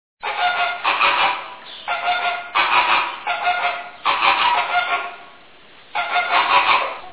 głosy